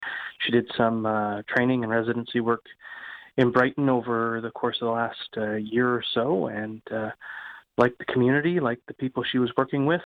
Mayor of Brighton Brian Ostrander: